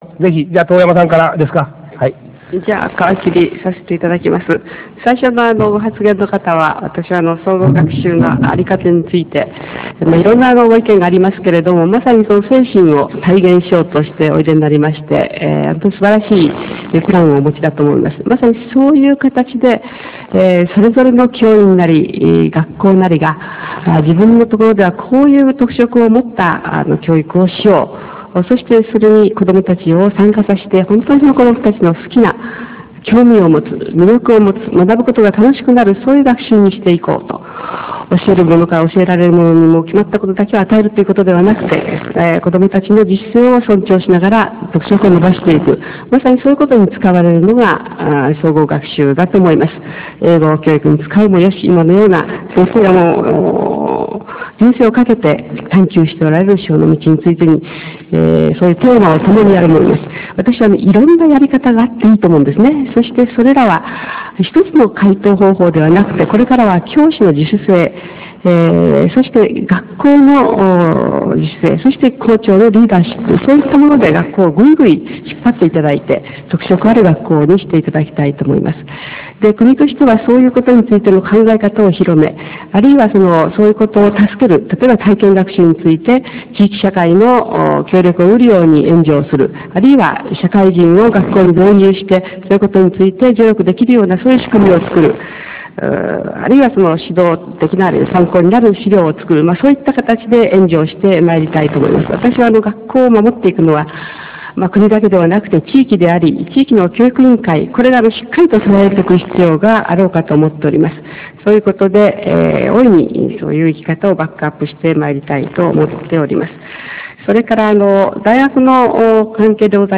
各種講演会の様子
タウンミーティング２(長岡市)